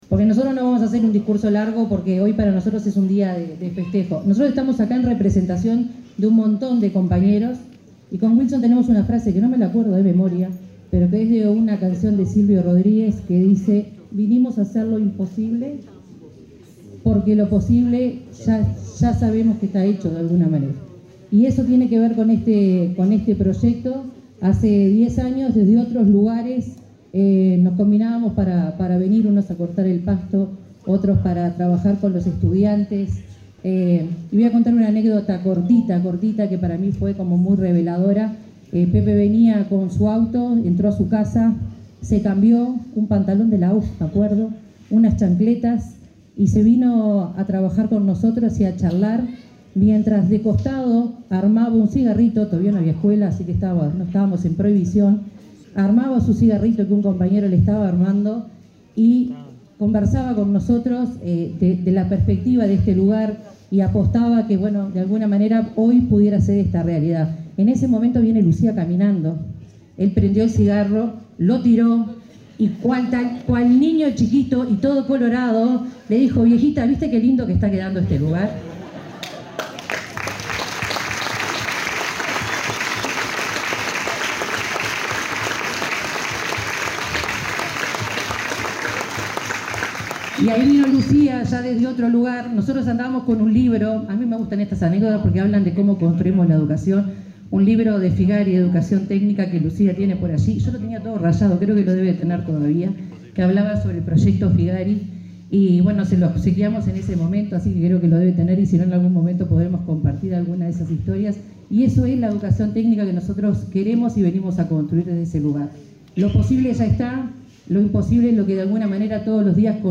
Palabras de autoridades de la educación en aniversario de escuela agraria en Rincón del Cerro
Palabras de autoridades de la educación en aniversario de escuela agraria en Rincón del Cerro 02/09/2025 Compartir Facebook X Copiar enlace WhatsApp LinkedIn La directora general de Educación Técnico-Profesional, Virginia Verderese, y el presidente de la Administración Nacional de Educación Pública, Pablo Caggiani, se expresaron en el acto conmemorativo del 10.° aniversario de la Escuela Agraria Montevideo, anexo Rincón del Cerro.